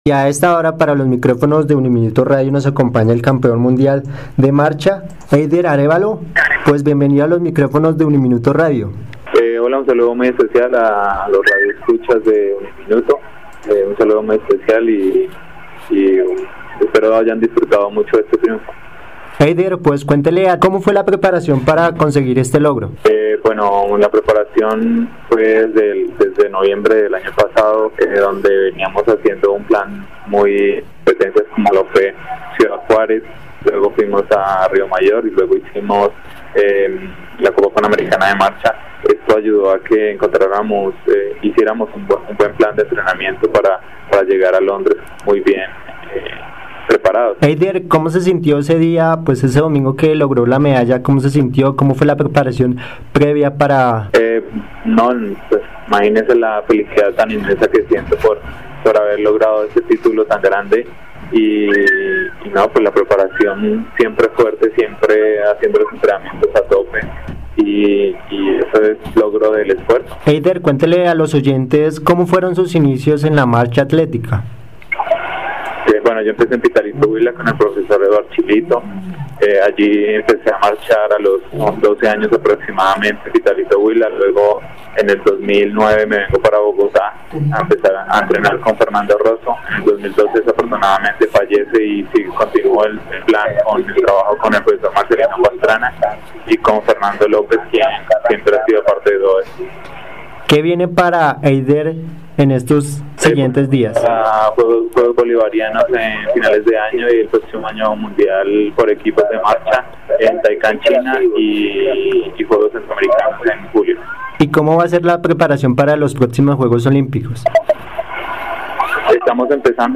En diálogo con UNIMINUTO Radio estuvo Eider Arévalo, actual campeón mundial de marcha 20km en Londres, Inglaterra.